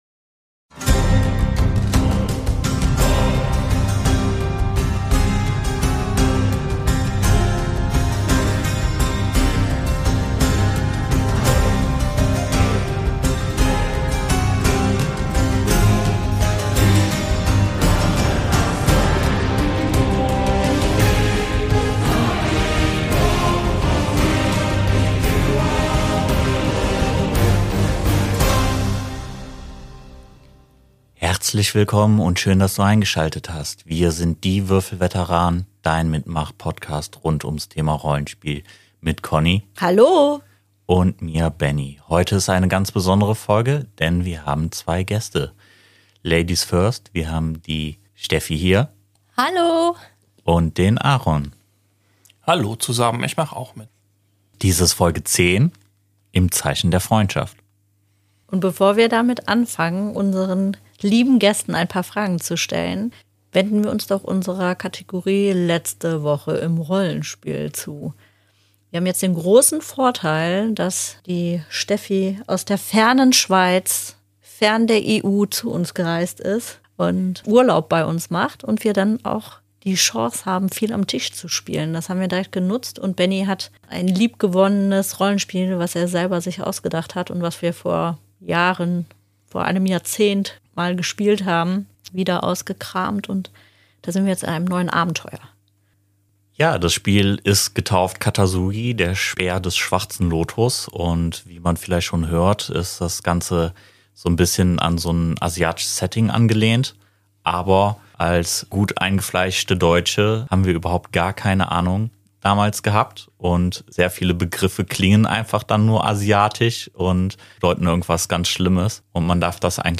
Die beiden sind mutig genug, sich hinter das Mikrofon zu wagen und einige Fragen zu beantworten. Hört rein und beantwortet die Fragen für euch selbst mit oder noch besser nehmt sie mit in eure Rollenspielrunden!